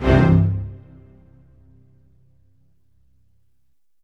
ORCHHIT C2-R.wav